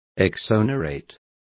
Complete with pronunciation of the translation of exonerating.